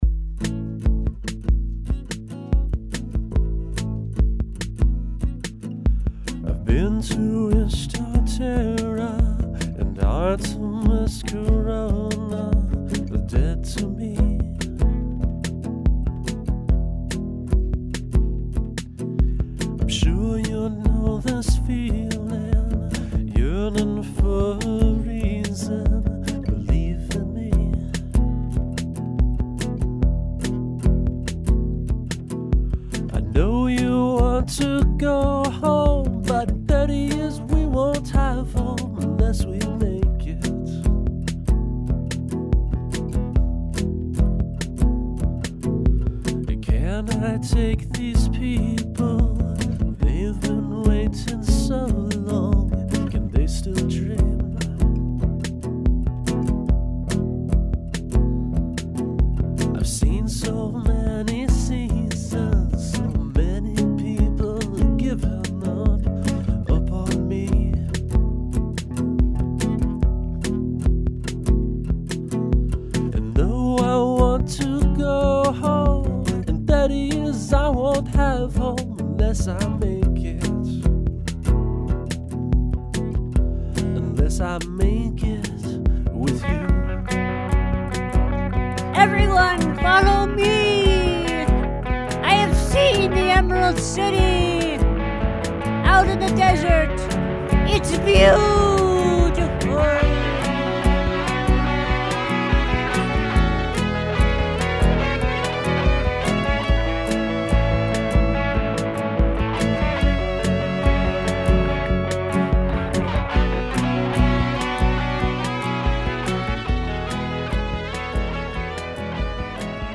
I had the whole melody and the first guitar part on the instrumental outro worked out, but nothing else, not even the chords.
I'm really into the electric guitar.